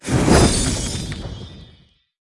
Media:RA_Wizard_Evo.wav UI音效 RA 在角色详情页面点击初级、经典和高手形态选项卡触发的音效